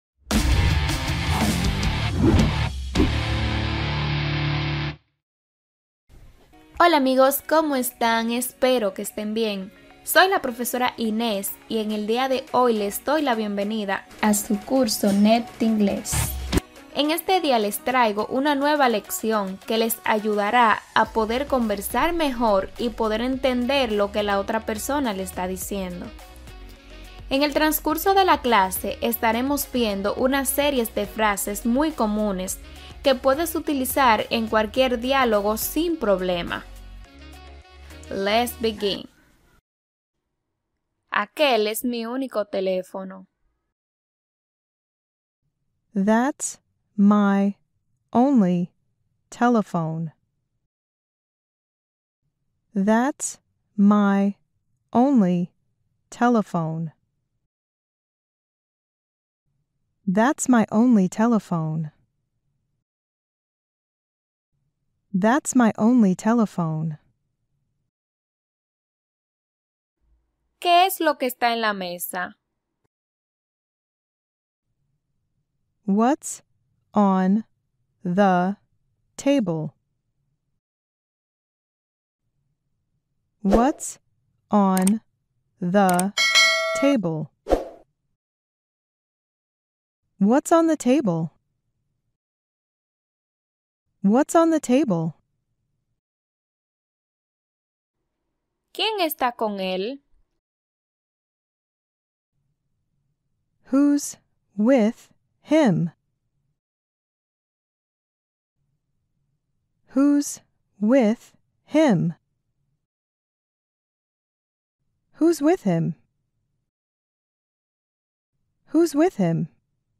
Si aprendes estas frases, tu inglés avanzará de 0 a | Aprende inglés fácil y rápido Inglés Americano – Pronunciando oraciones de forma natural (Lección 6)